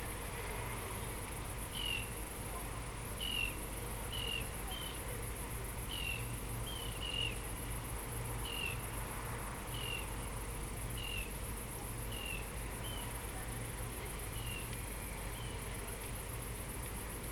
Carrán común
Canto
A súa chamada aguda, un repetido “kirrik”, escoitase frecuentemente cando está voando ou alimentándose en grupo, o que o converte nunha presenza audible nos seus hábitats costeiros.